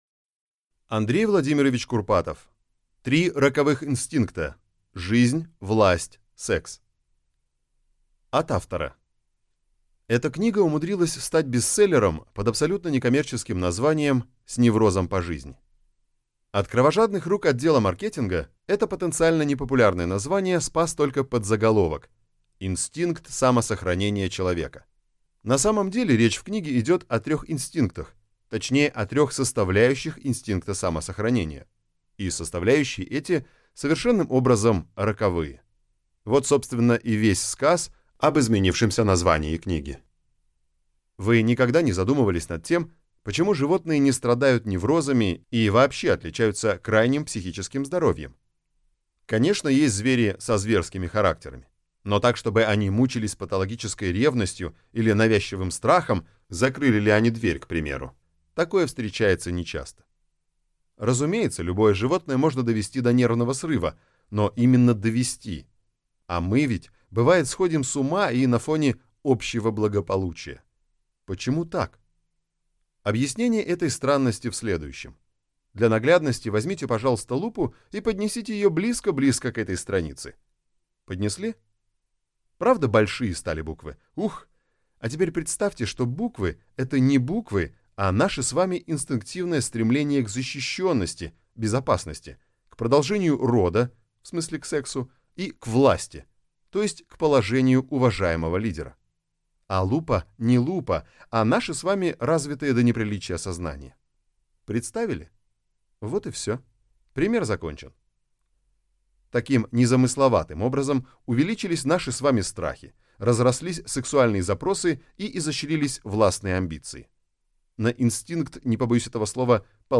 Аудиокнига Три инстинкта: Жизнь, Власть, Секс. Универсальные правила | Библиотека аудиокниг